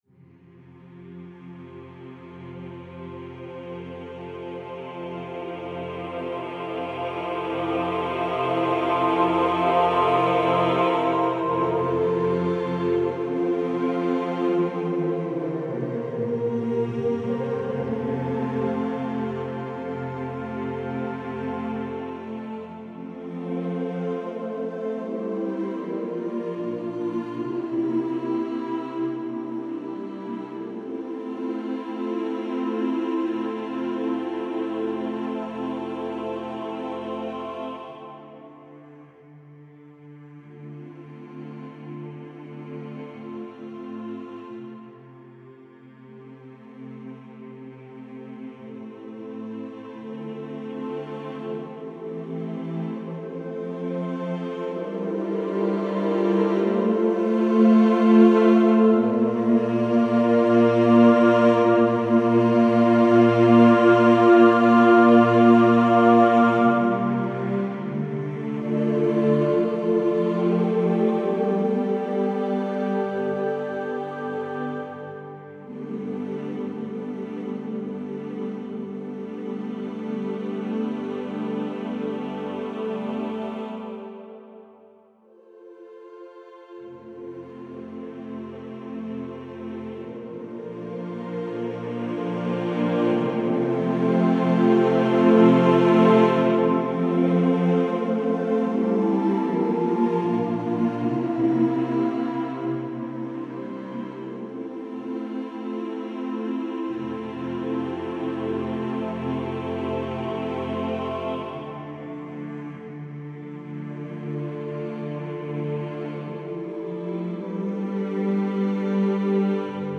I'm back with another piece of choral music!